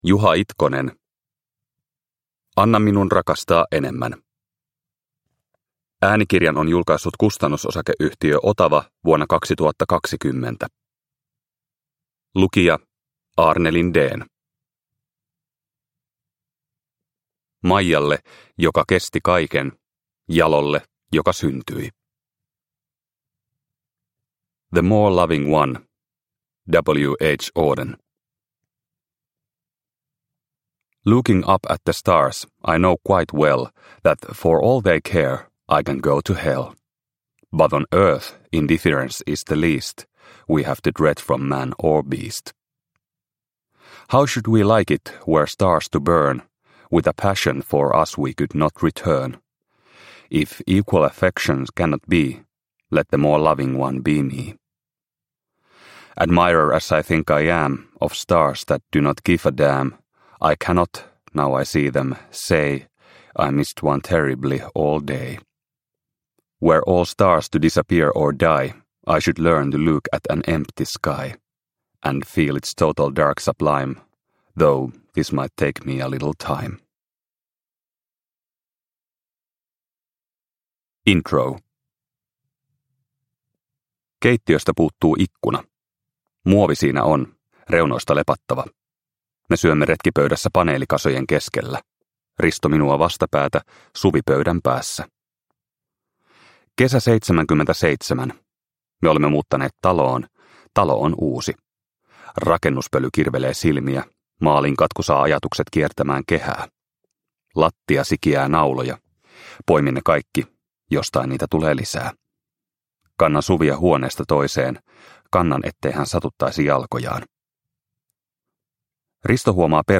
Anna minun rakastaa enemmän – Ljudbok – Laddas ner